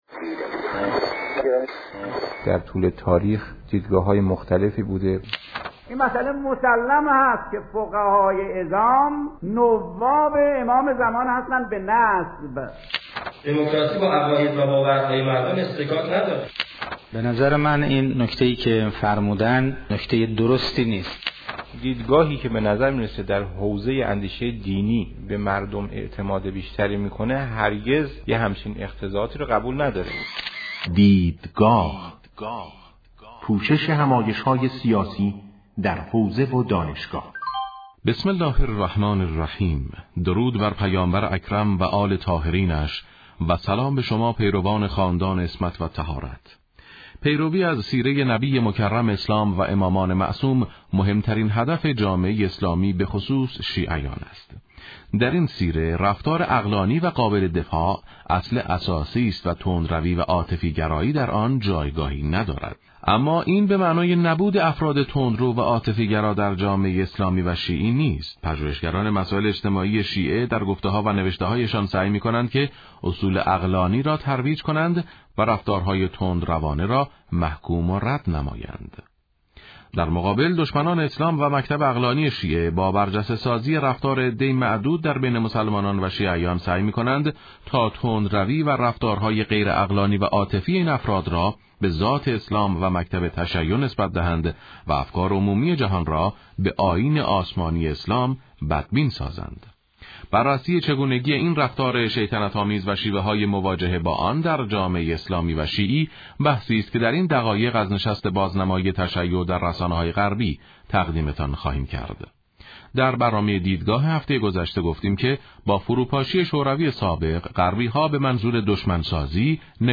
سخنان
پیرامون قبایل و قومیت ها در رادیو معارف